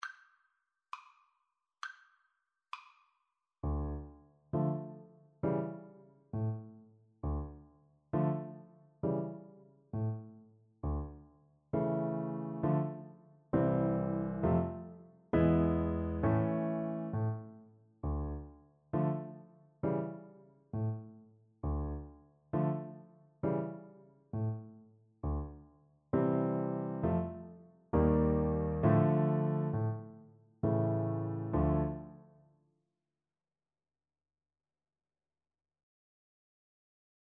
Moderato
Classical (View more Classical Piano Duet Music)